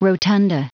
Prononciation du mot rotunda en anglais (fichier audio)
Prononciation du mot : rotunda